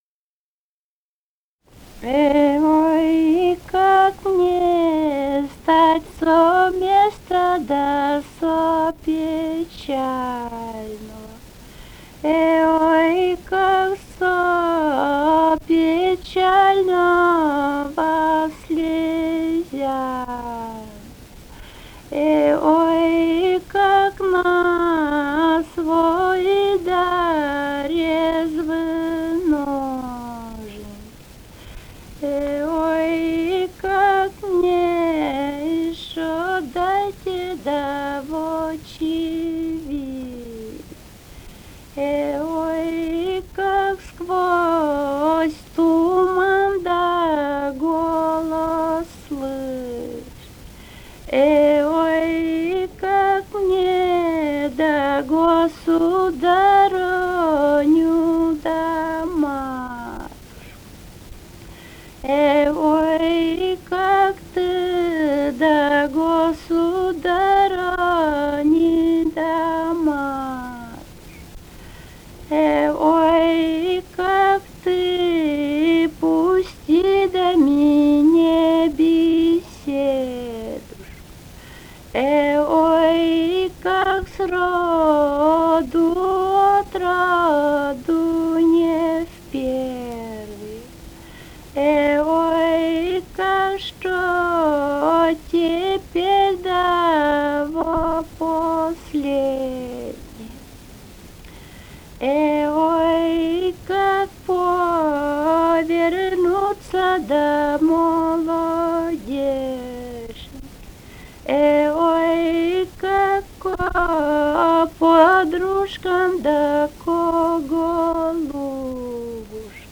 Этномузыкологические исследования и полевые материалы
Вологодская область, г. Кириллов, 1969 г. И1132-24